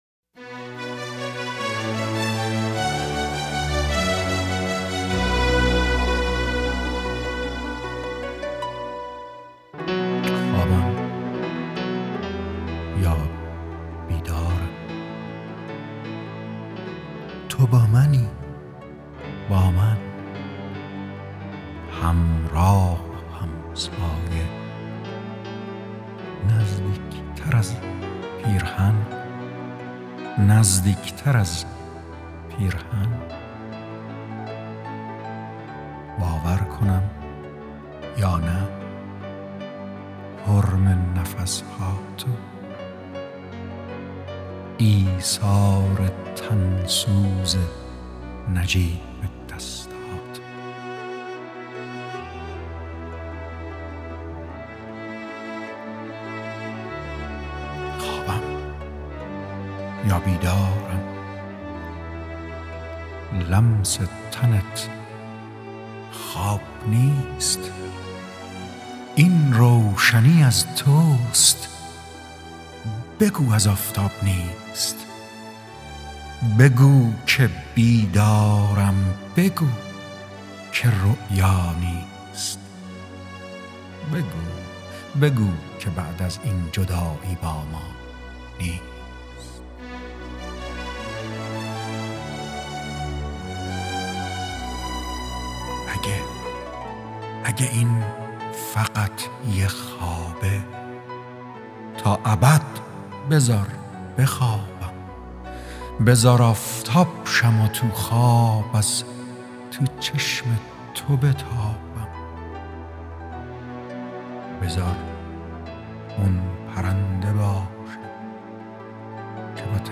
دانلود دکلمه خوابم یا بیدارم با صدای ایرج جنتی عطایی
گوینده :   [ایرج جنتی عطایی]